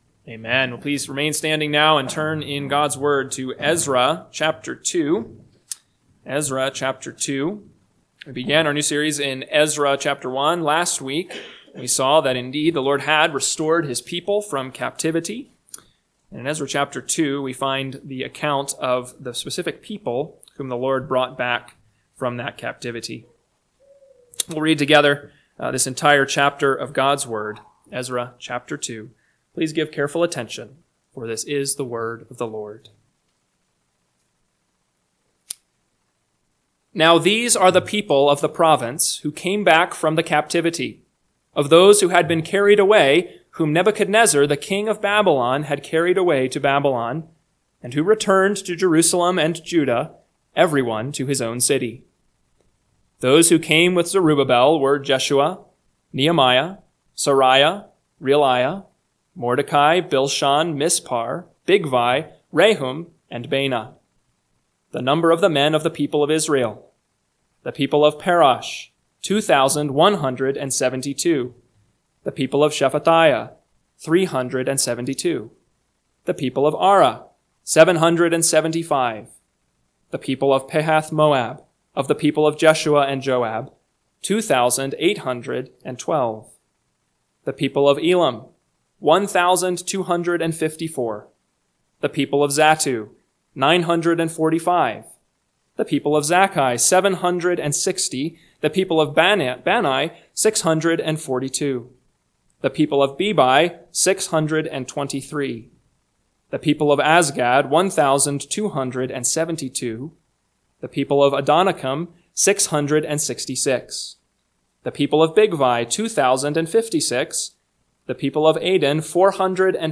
PM Sermon – 2/23/2025 – Ezra 2 – Northwoods Sermons